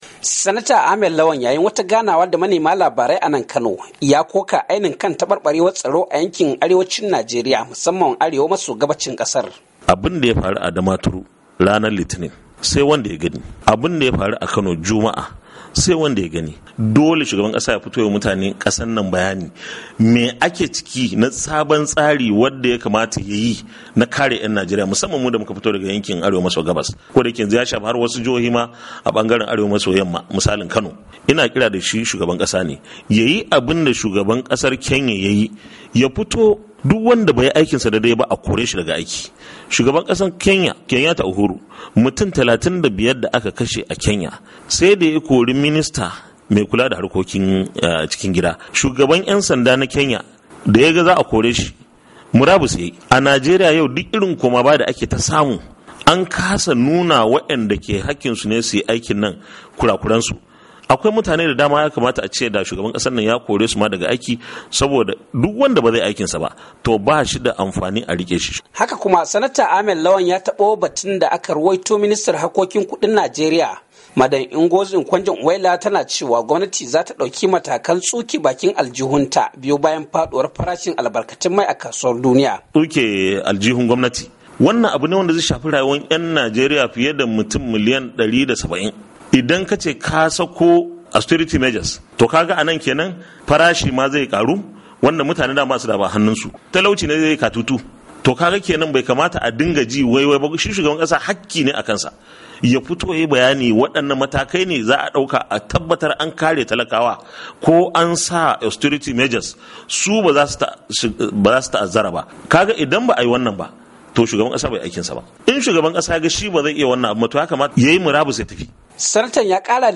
Sanata Ahmed Lawan, mai wakiltar mazabar Yobe, ta Arewa a majalisar dattawan najeriya ya bukaci shugaba Goodluck Jonathan, da ya bayyanawa ‘yan kasar sabbin matakan da gwamnati ke dauka domin dakile ayyukan ‘yan kungiyar Boko Haram a kasar. Sanatan ya bukaci hakane a yayin wata ganawa da yayi...